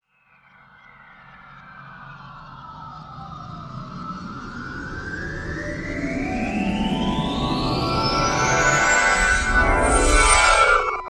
SPACECHIME.wav